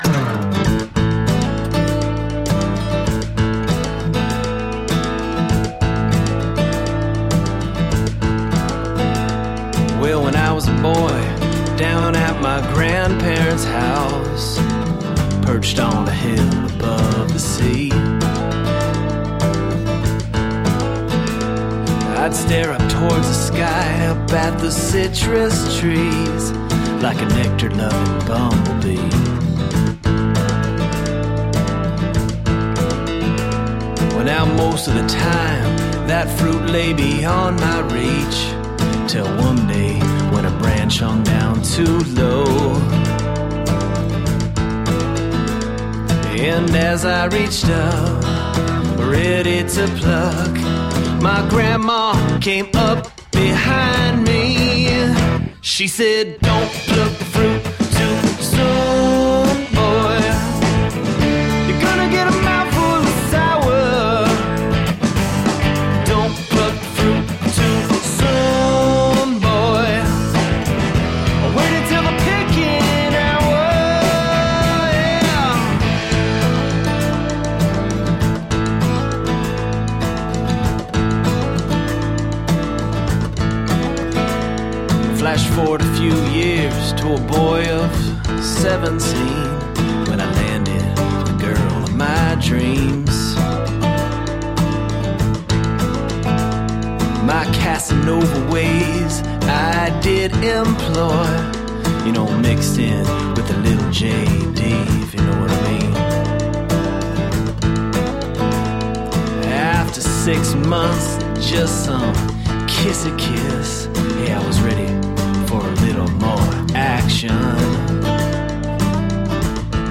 Tagged as: Alt Rock, Folk-Rock